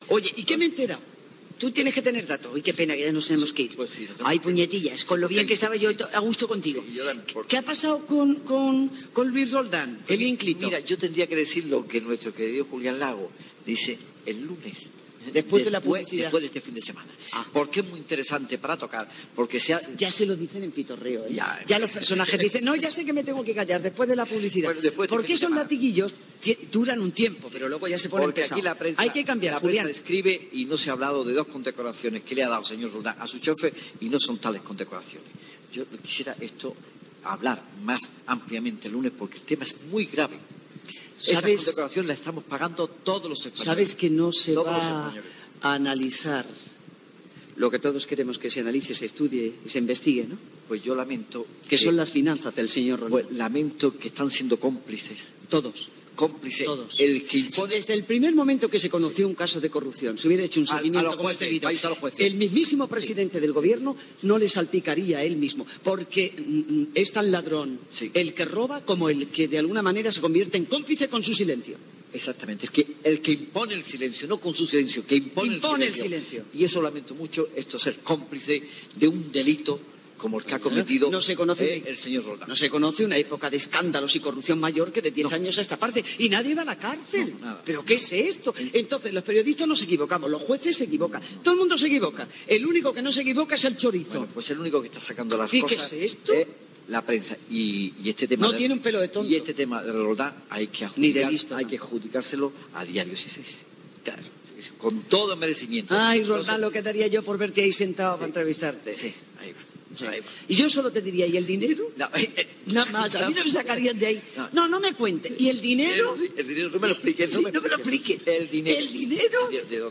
La presentadora i Jaime Peñafiel parlen del cas de Luis Roldán, director de la Guardia Civil.
Info-entreteniment